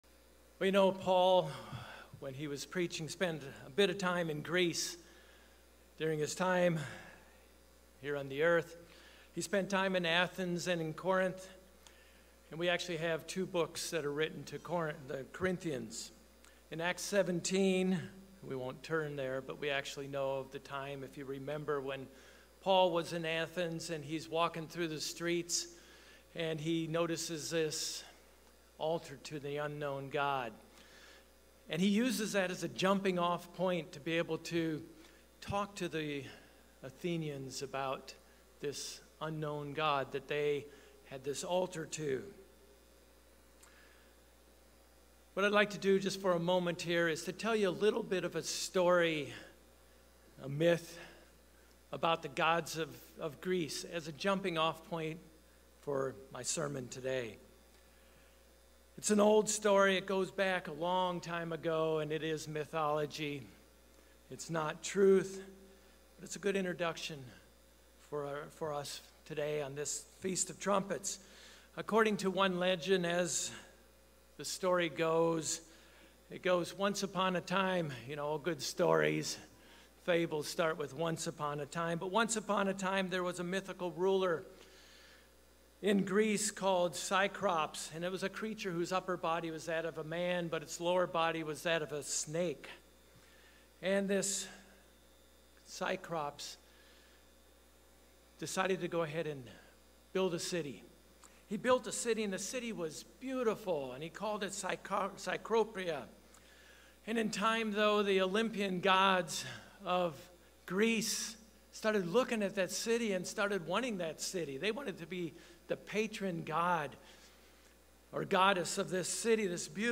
Sermons
Given in Orlando, FL